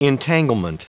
Prononciation du mot : entanglement
entanglement.wav